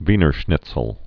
(vēnər shnĭtsəl)